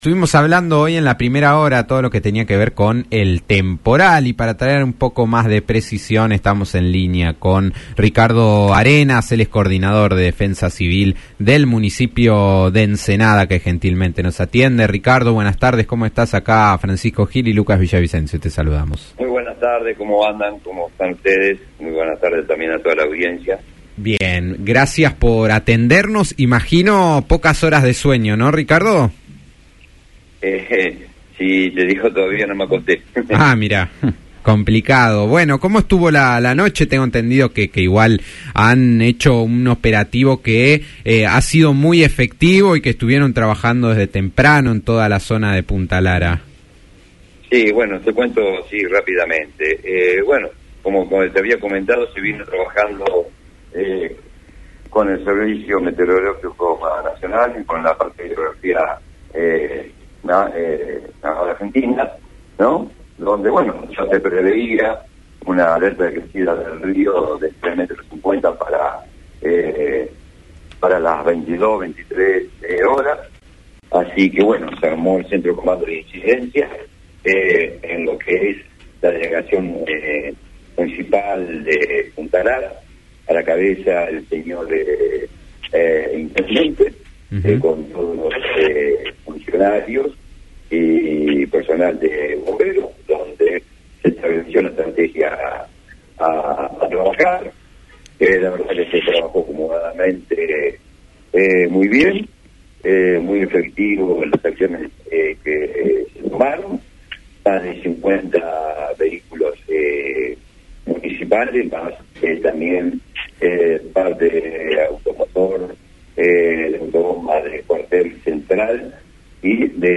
en diálogo con Después del Mediodía (fm 90.9)